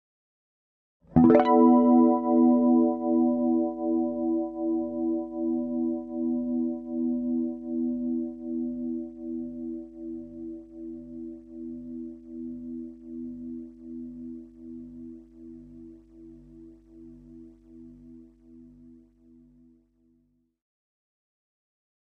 Electric Guitar Harmonics On 7th Fret With Chorus